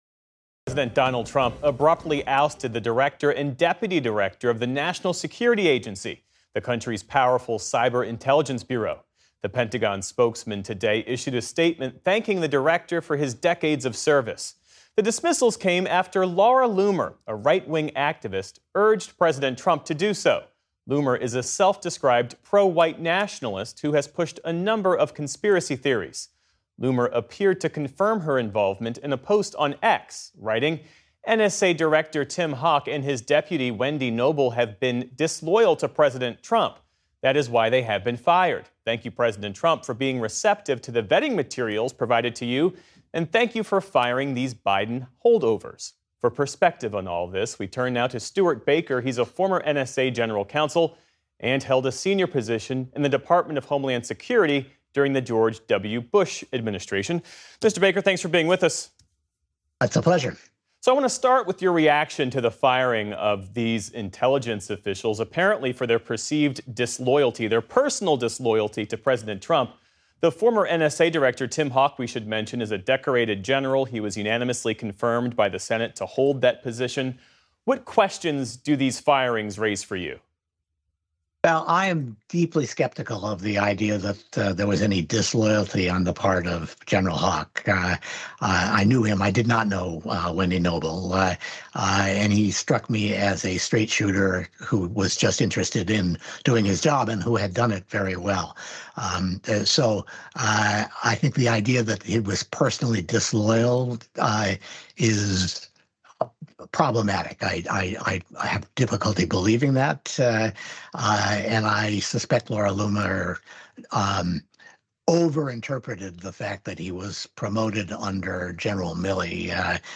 Geoff Bennett discussed more with Stewart Baker, a former NSA general counsel.